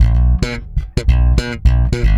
-AL DISCOEND.wav